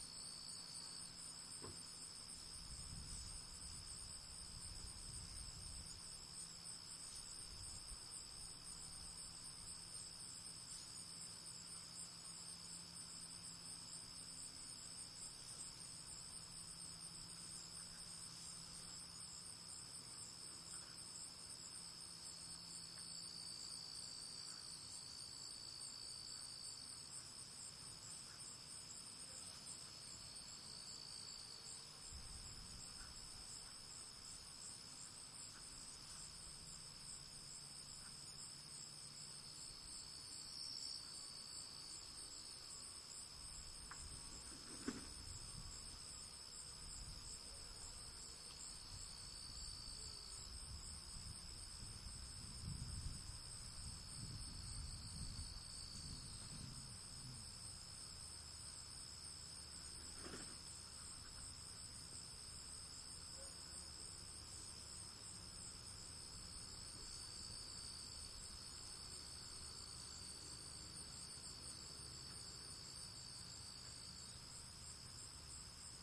Ambient Noises » Night Sounds 1
描述：Slightly louder crickets and cars in Thorndale Texas at night.
标签： ambience night crickets country nature fieldrecording
声道立体声